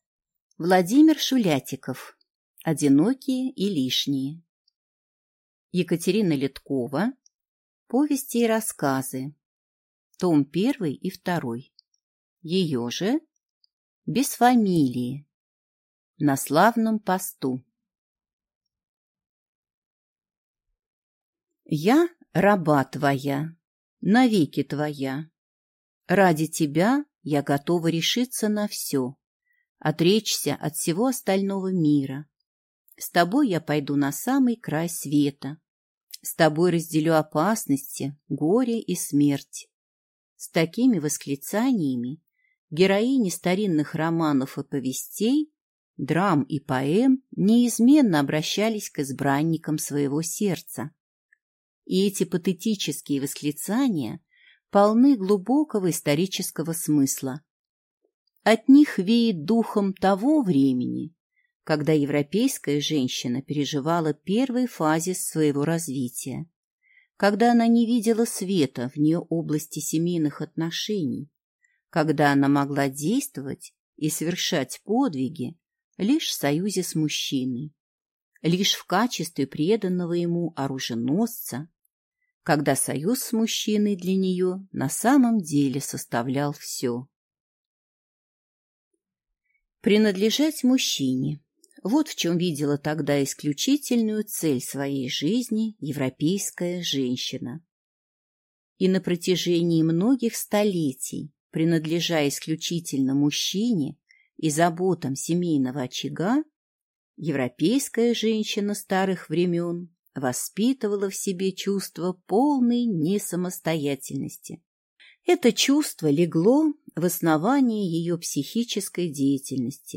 Аудиокнига «Одинокие и лишние».